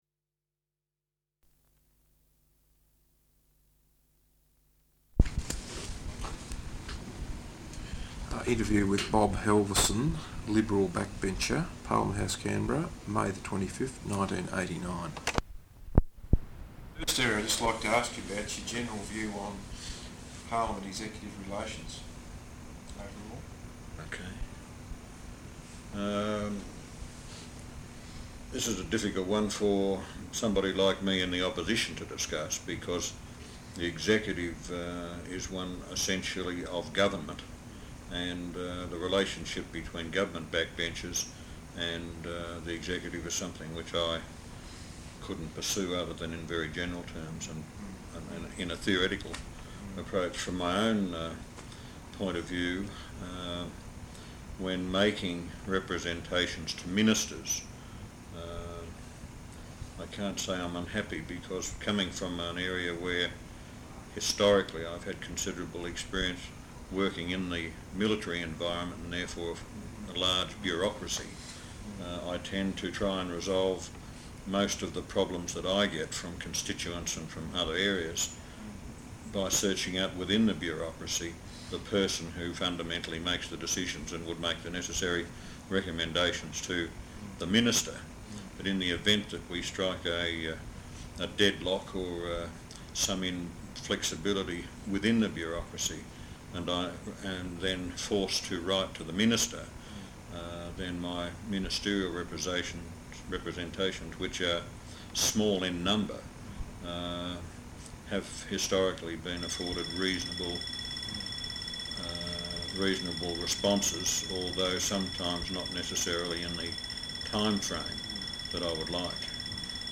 Interview with Bob Halverson, Liberal backbencher, Parliament House, Canberra May 25th, 1989.